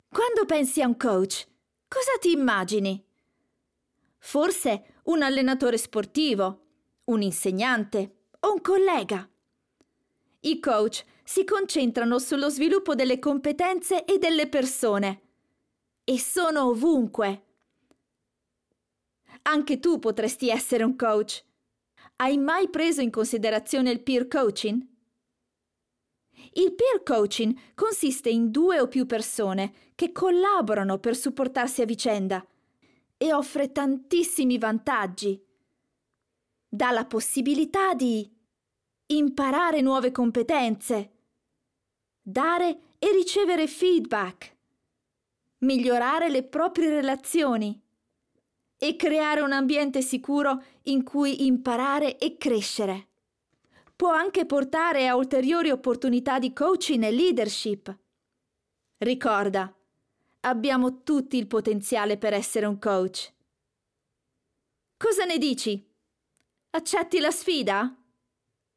E-learning
I am a native Italian voice over artist.
My voice is young, fresh and energetic.
I can record in Italian with a neutral accent or English with great diction and a great soft Italian accent.
Microphone: Rode NT1A
Mezzo-SopranoSoprano